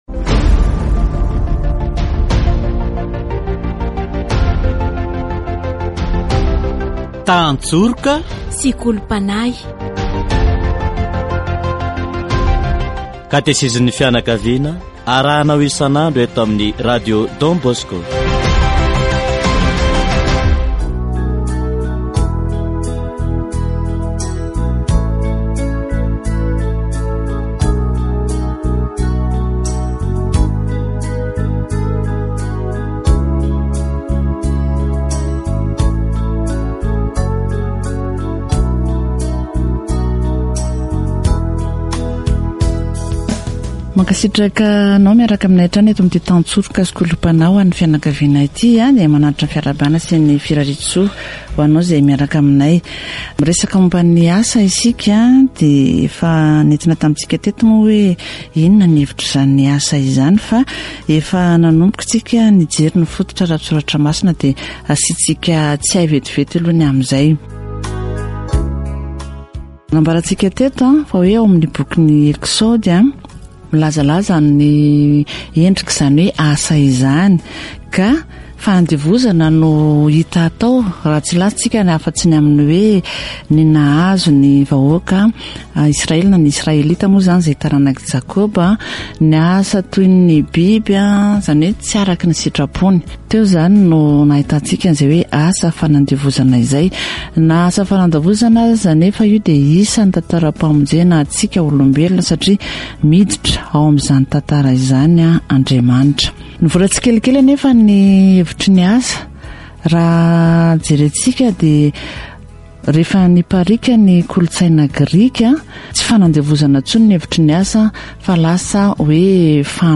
Catéchèse sur le travail